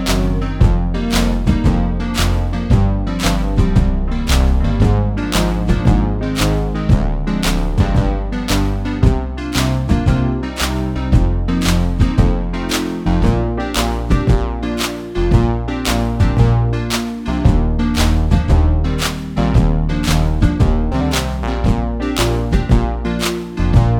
no sax solo Pop (1970s) 3:21 Buy £1.50